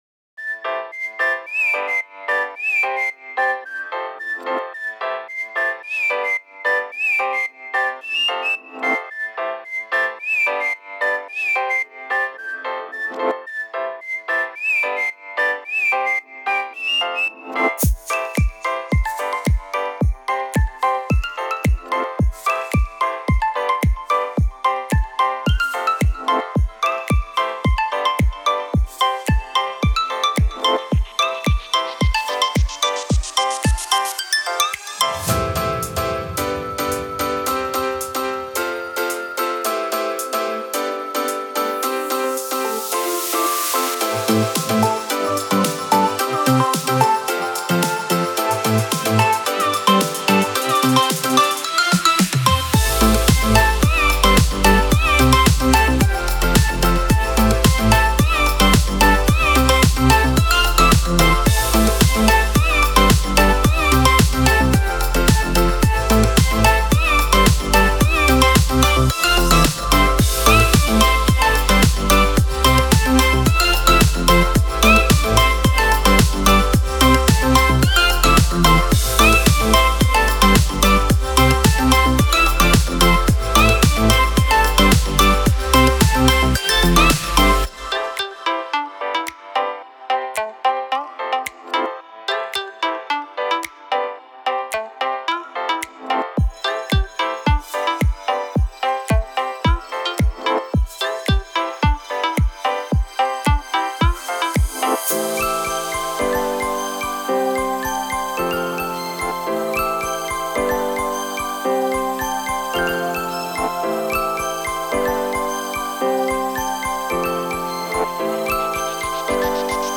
الکترونیک پاپ